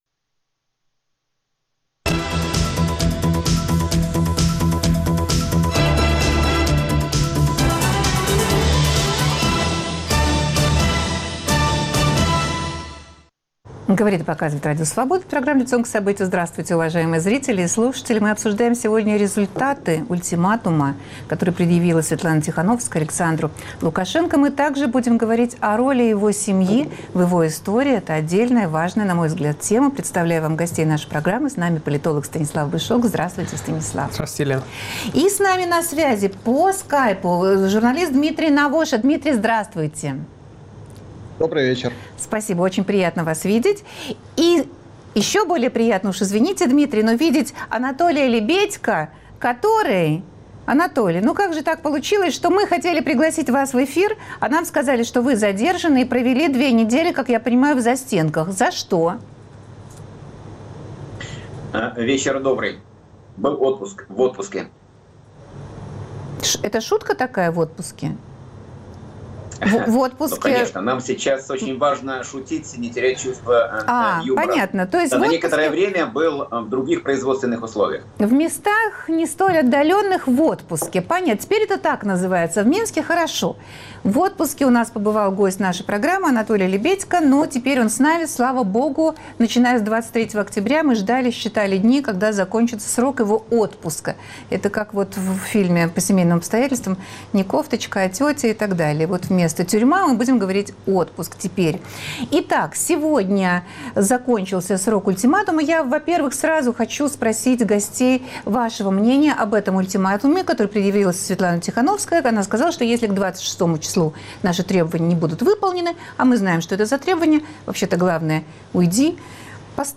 Кто и что способно заставить уйти президента республики Беларусь? В обсуждении участвуют политолог